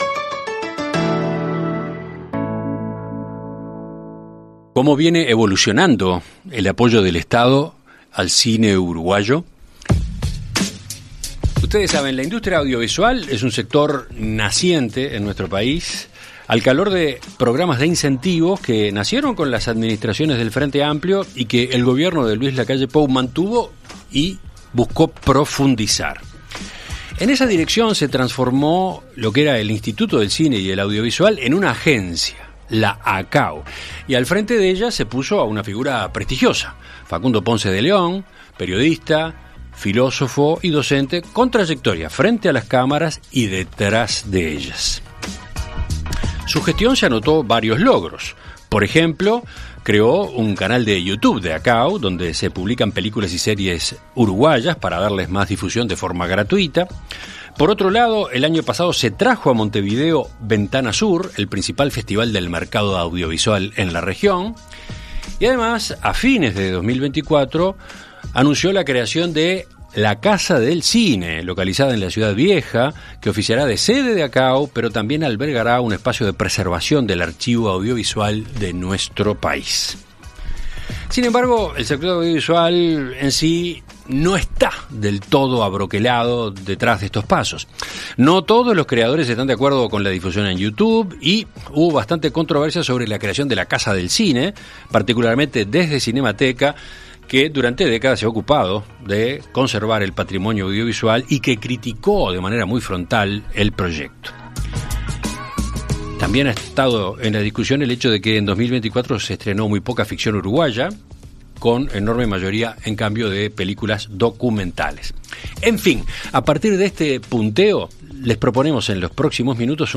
En Perspectiva Zona 1 – Entrevista Central: Facundo Ponce de León - Océano
Conversamos con su presidente, Facundo Ponce de León, para hacer un balance de su gestión.